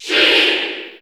Crowd cheers
Sheik_Cheer_English_PAL_SSBU.ogg